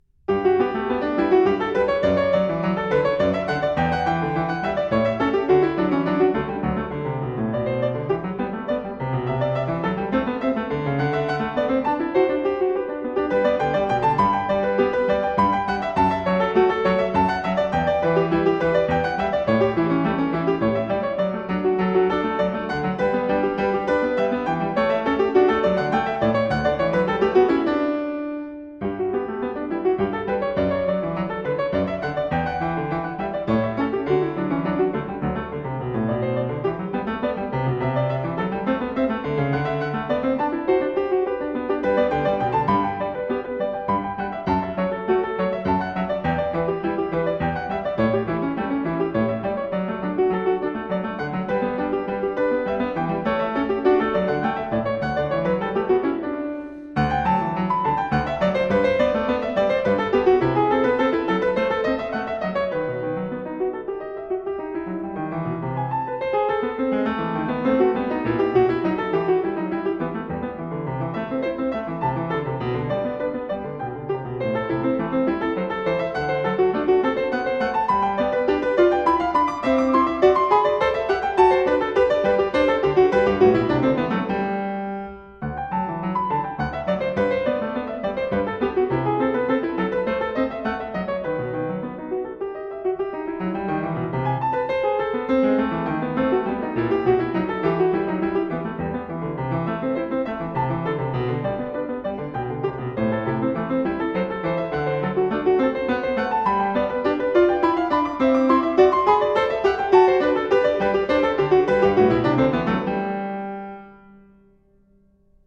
Kimiko Ishizaka - J.S. Bach- -Open- Goldberg Variations, BWV 988 (Piano) - 02 Variatio 1 a 1 Clav.